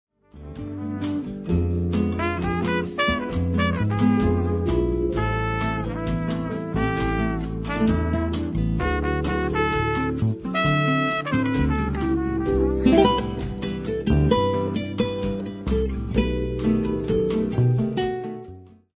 Instrumental Album of the Year